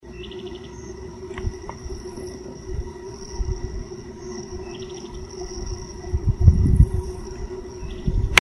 Bran-colored Flycatcher (Myiophobus fasciatus)
Life Stage: Adult
Location or protected area: Reserva Natural Urbana La Malvina
Condition: Wild
Certainty: Photographed, Recorded vocal